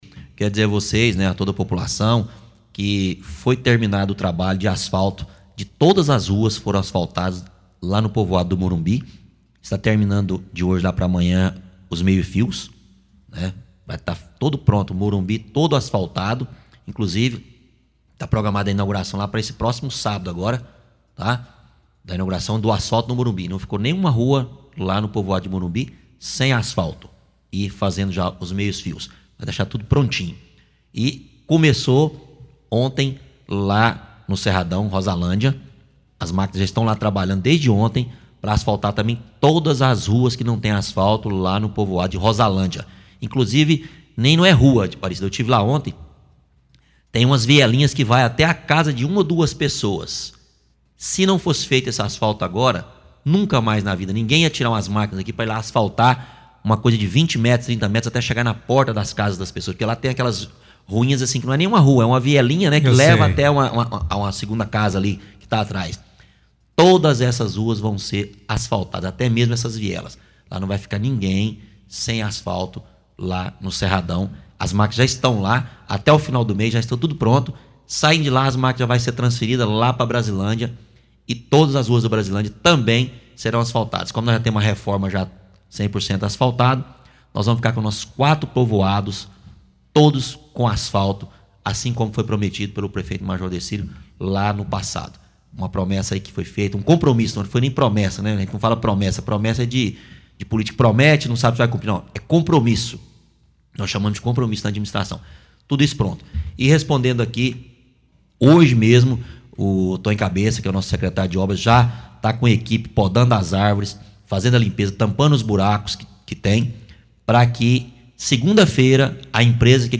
Sete bairros serão contemplados, entre eles um dos maiores e mais tradicionais, que é o Setor São José. O vice – prefeito, Júnior da Receita, comenta essas obras no áudio abaixo.
FALA-DO-VICE-PREFEITO.mp3